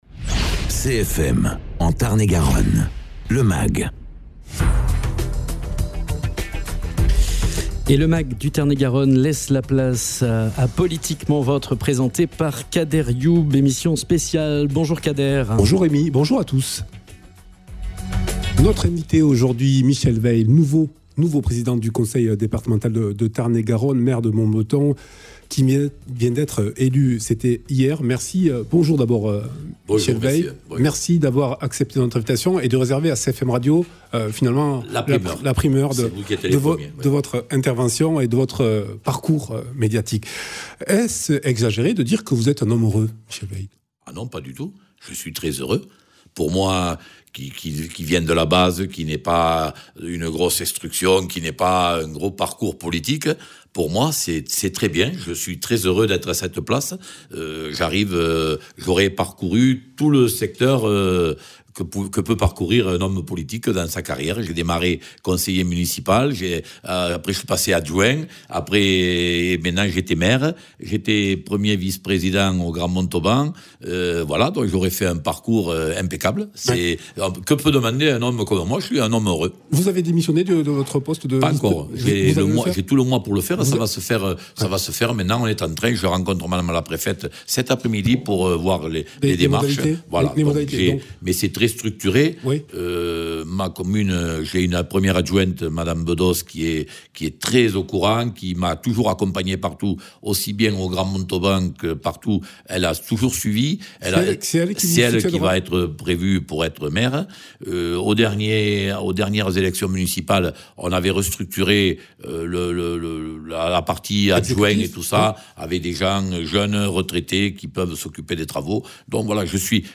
Invité(s) : Michel Weill, Président du conseil départemental de Tarn-et-Garonne